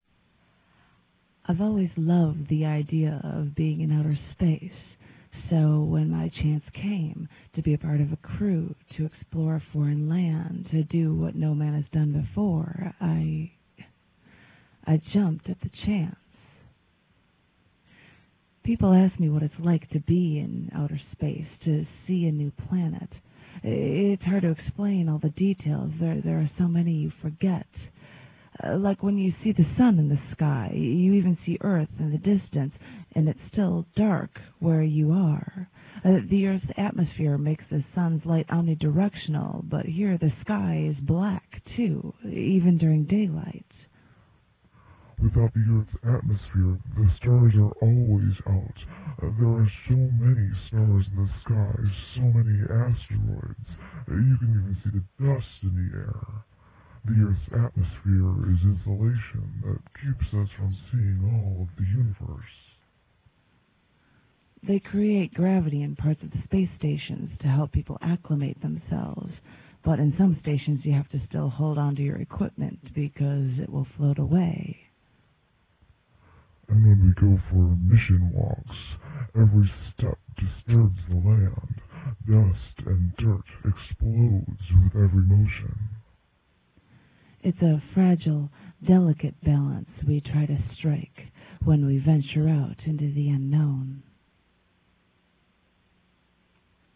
the poem
real audio studio vocals, 1:36